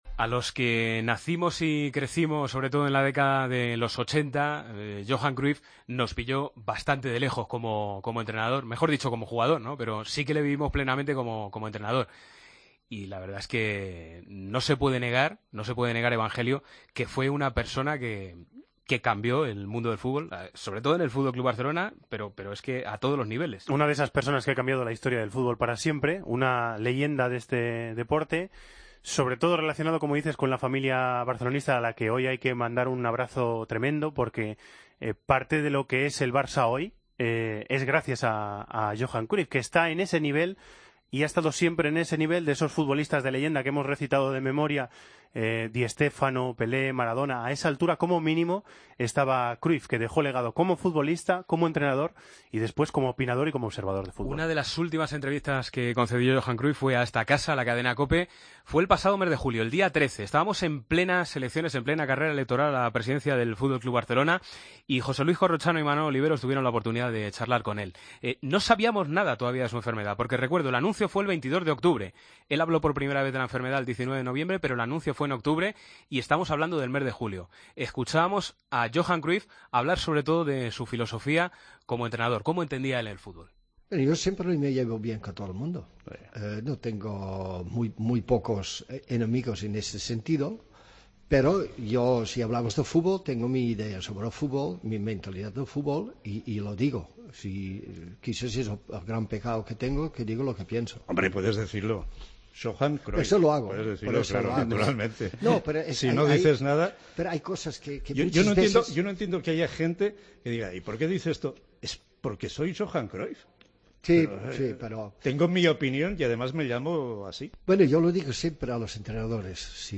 Entrevistas a Hristo Stoichkov y el 'Pitu' Abelardo.